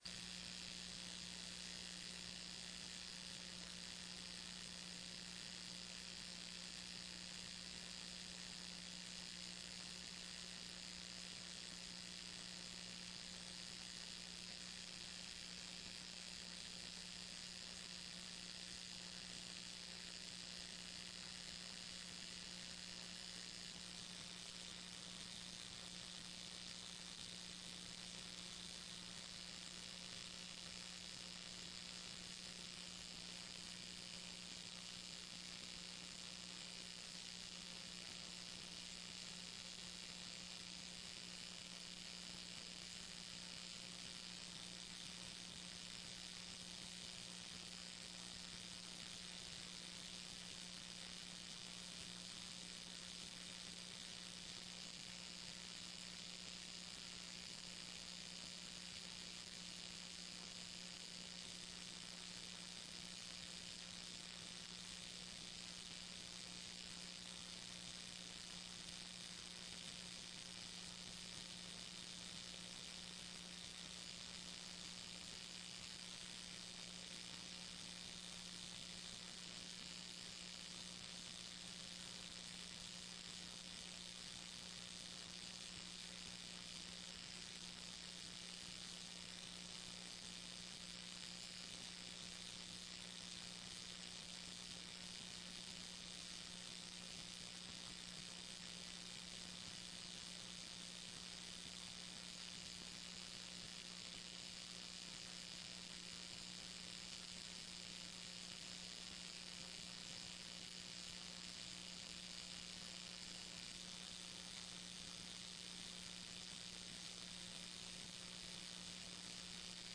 TRE-ES - Áudio da sessão 01.10.14